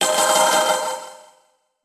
短い時間で終わる音なので、繰り返し鳴らされても気にならないでしょう。
シンプルながらも存在感のある音なので、通知を見逃す心配もありません。